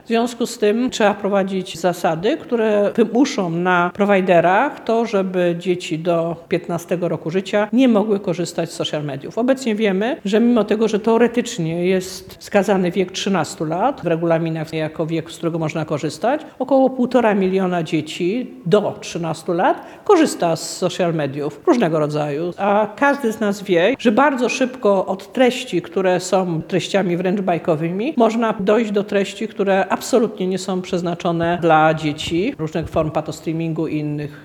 – Koalicja Obywatelska w najbliższym czasie złoży projekt dotyczący zakazu korzystania z mediów społecznościowych przez dzieci i młodzież do 15. roku życia – zapewniła w Lublinie wiceministra edukacji Katarzyna Lubnauer.